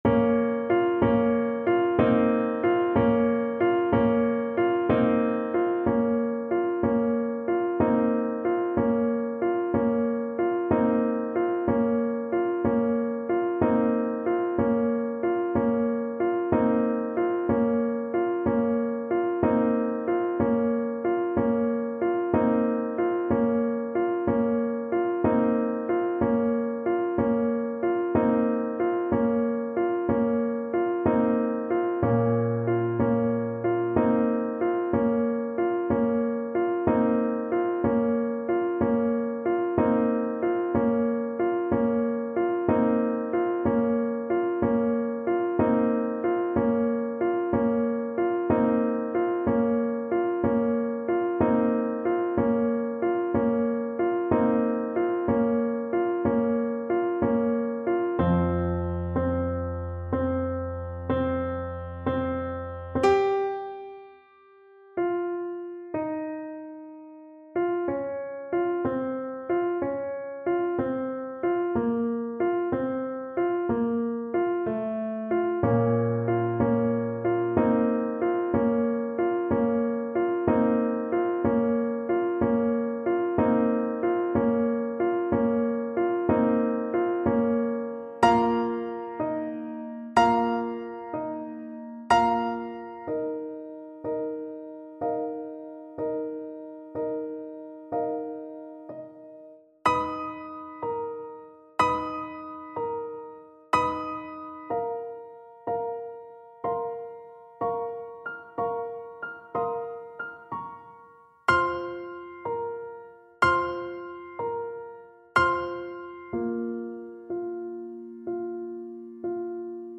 Trumpet
9/8 (View more 9/8 Music)
Bb minor (Sounding Pitch) C minor (Trumpet in Bb) (View more Bb minor Music for Trumpet )
Allegro moderato .=c.86 (View more music marked Allegro)
Classical (View more Classical Trumpet Music)